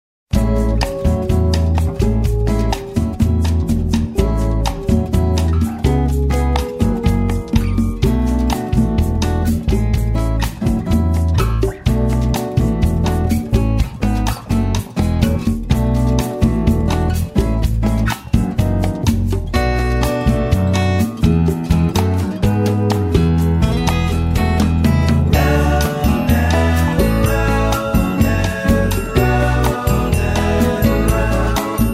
▪ The full instrumental track